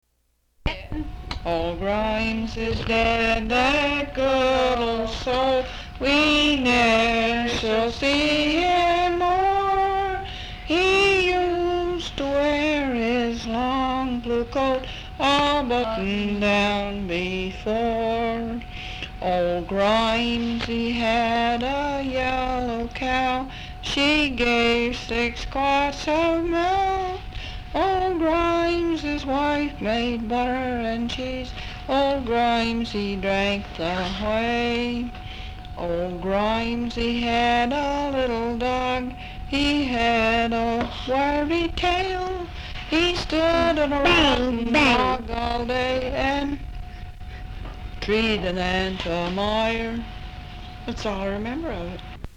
Folk songs, English--Vermont
sound tape reel (analog)